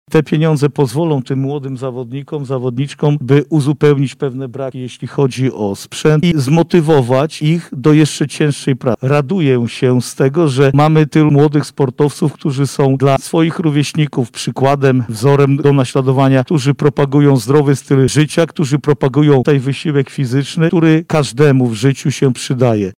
O szczegółach mówi Marszałek Województwa Lubelskiego Jarosław Stawiarski.